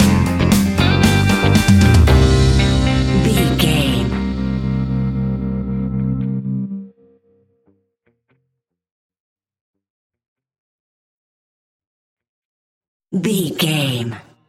Aeolian/Minor
dub
laid back
chilled
off beat
drums
skank guitar
hammond organ
percussion
horns